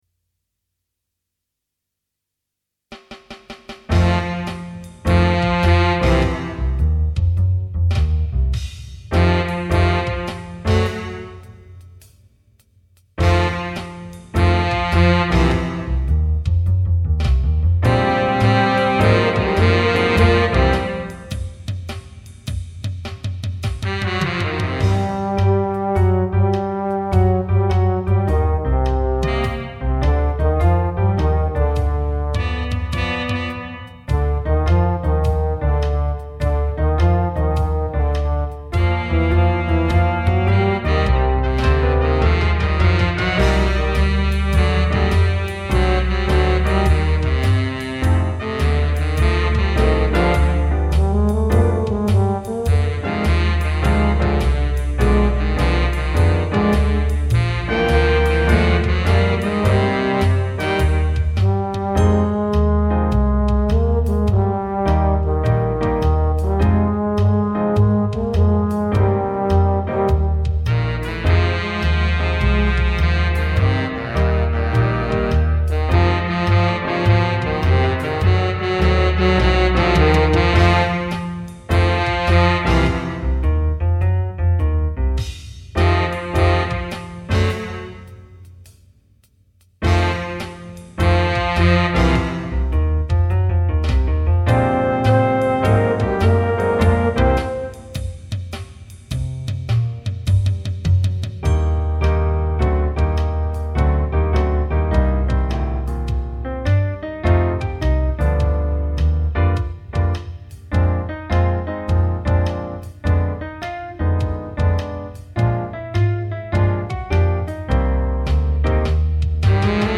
minus Instrument 3